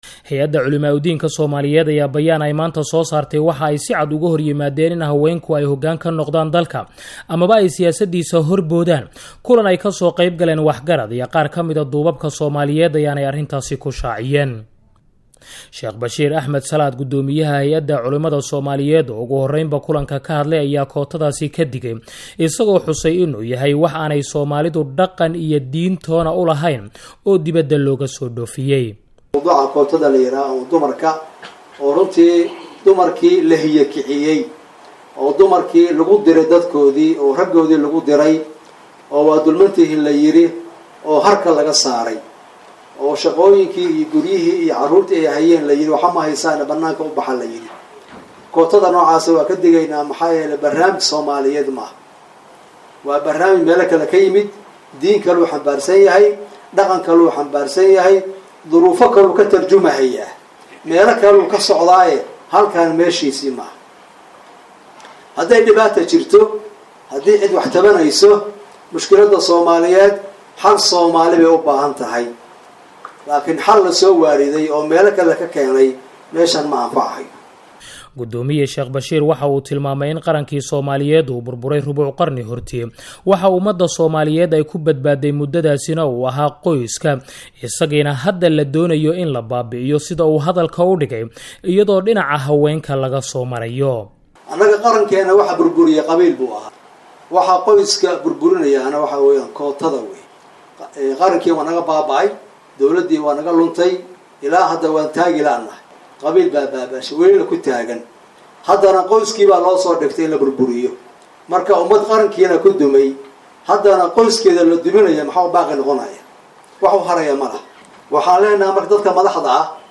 Warbixinta Hey'ada Culimada